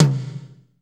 Index of /90_sSampleCDs/Northstar - Drumscapes Roland/DRM_Slow Shuffle/KIT_S_S Kit 1 x
TOM S S H13R.wav